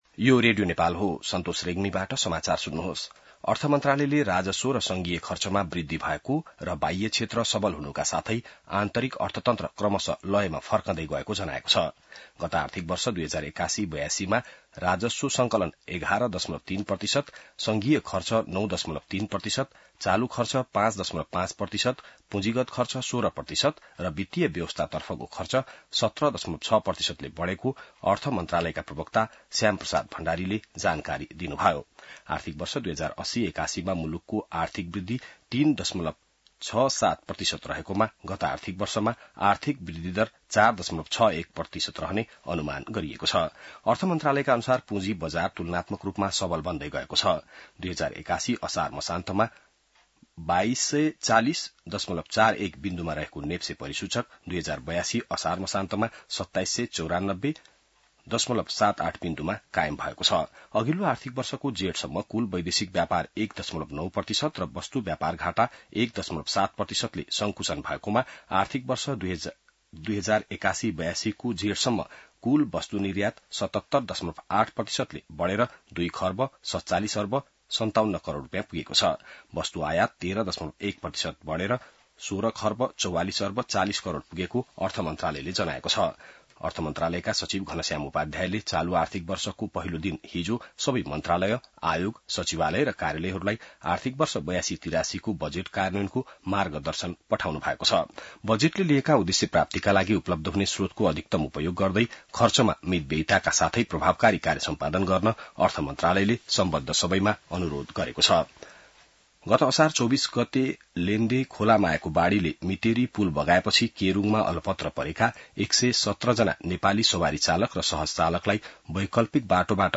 An online outlet of Nepal's national radio broadcaster
बिहान ६ बजेको नेपाली समाचार : २ साउन , २०८२